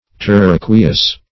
Search Result for " terraqueous" : The Collaborative International Dictionary of English v.0.48: Terraqueous \Ter*ra"que*ous\, a. [L. terra the earth + E. aqueous.] Consisting of land and water; as, the earth is a terraqueous globe.